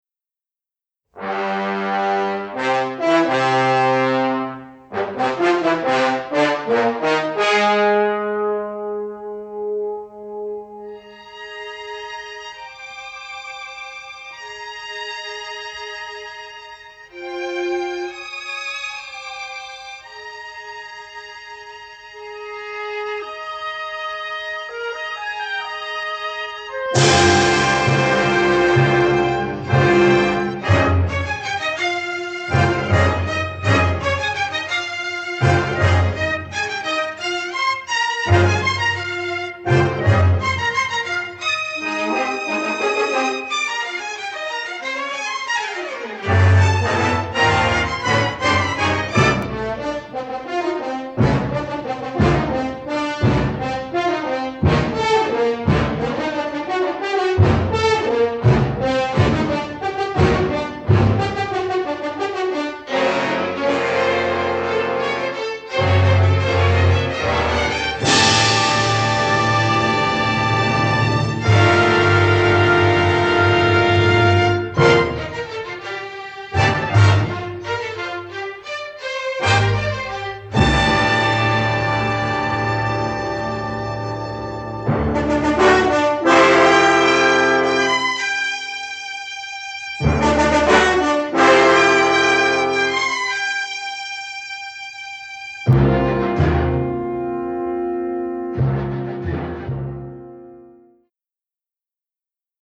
western score
dynamic entrance for French horns
complete score mastered in mono from print takes